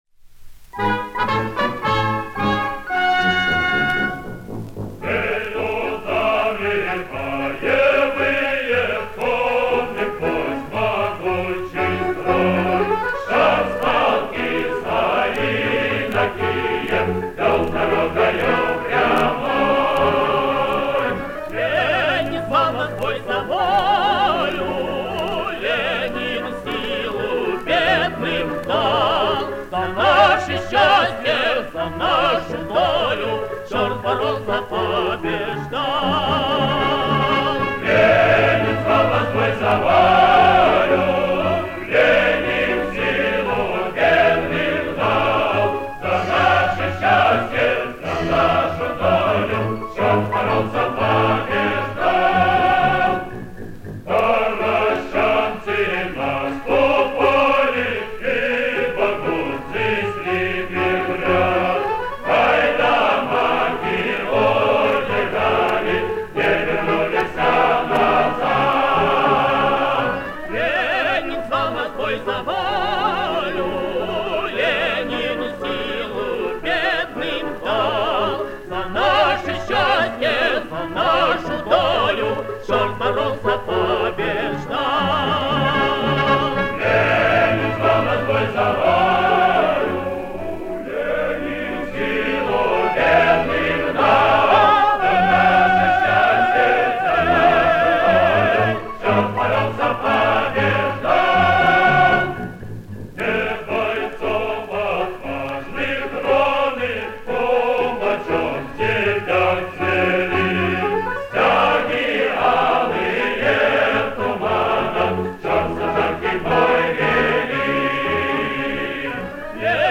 Оцифровал собственный экземпляр пластинки.